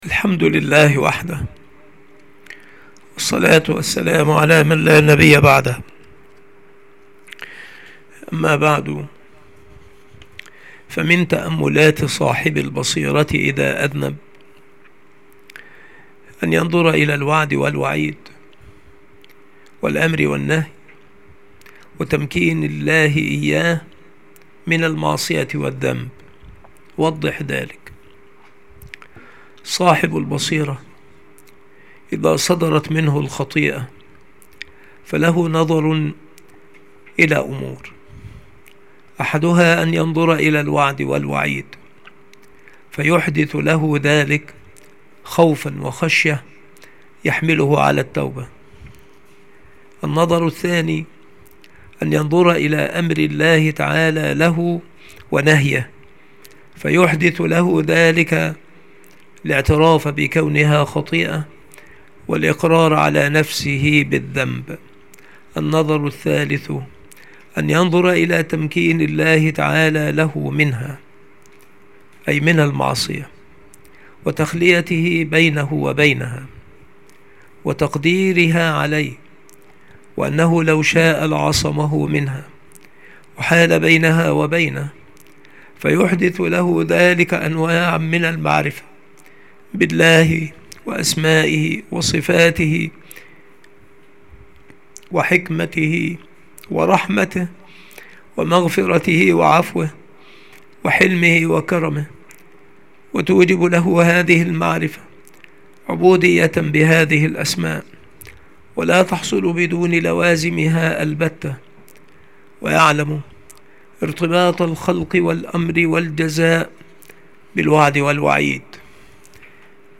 المحاضرة
مكان إلقاء هذه المحاضرة المكتبة - سبك الأحد - أشمون - محافظة المنوفية - مصر